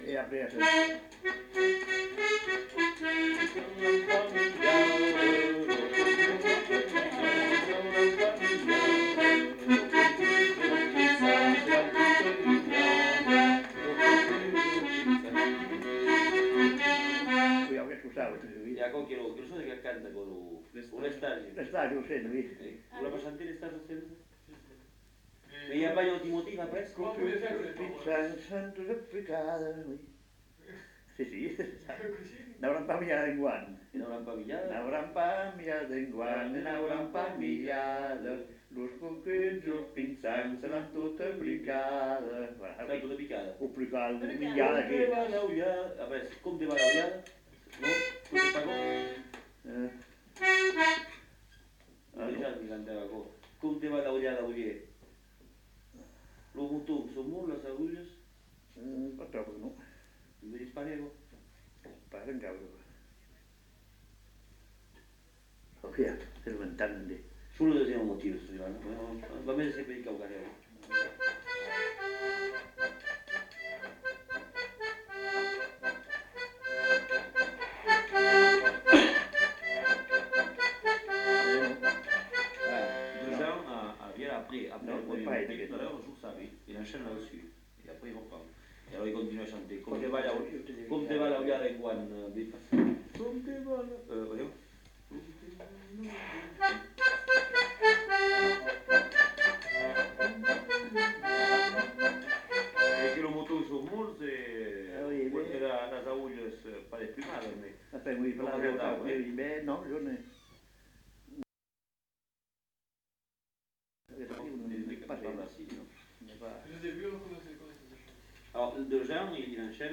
Lieu : Lencouacq
Genre : chant
Type de voix : voix d'homme
Production du son : chanté
Instrument de musique : accordéon diatonique
Danse : rondeau